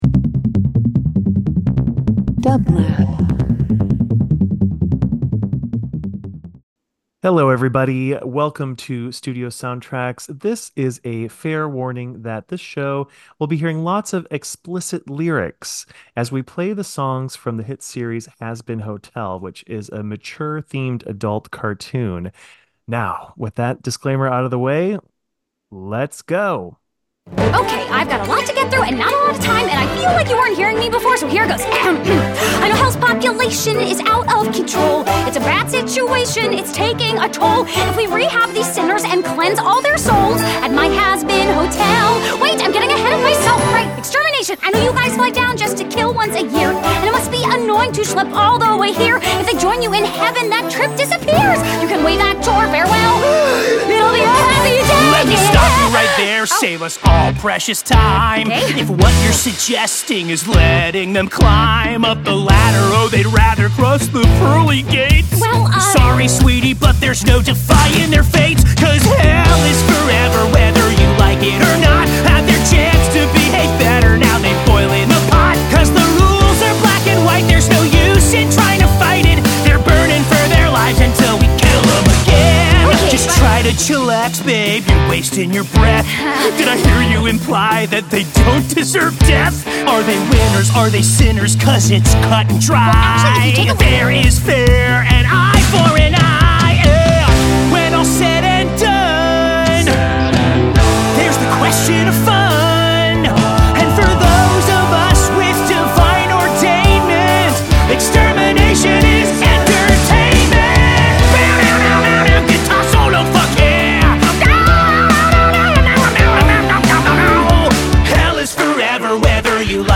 Studio Soundtracks takes listeners behind the scenes of how music is crafted for film and television by hearing directly from composers, songwriters and music professionals in the Entertainment Industry. Listen to inspiring conversations about composition and hear works from Emmy, Grammy, and Oscar-winning film scores on the show.